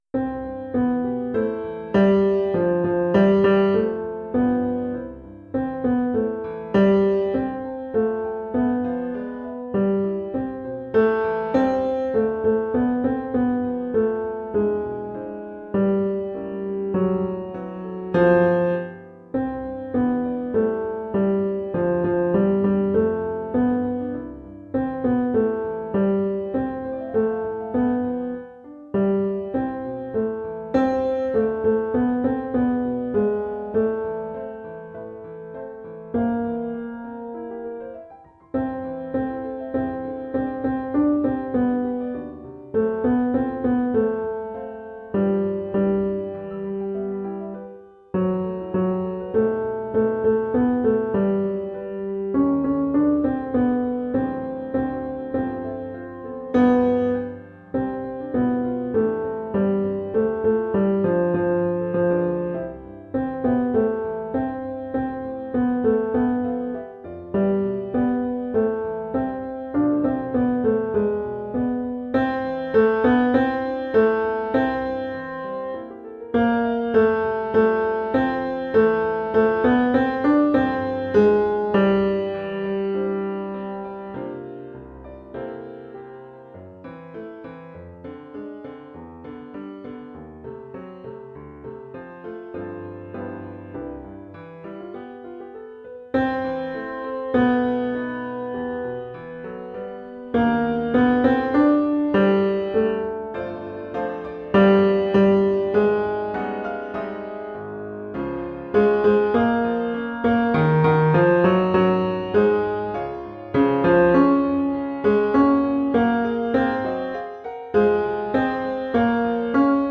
Practice Recordings
Have Yourself bass